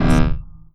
SCIFI_Energy_Pulse_01_mono.wav